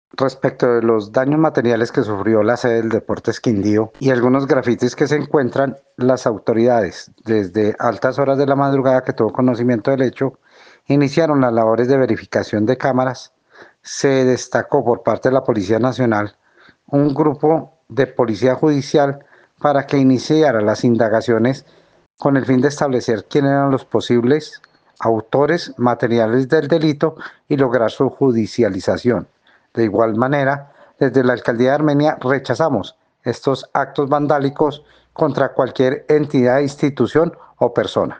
Secretario de Gobierno de Armenia sobre acto de vandalismo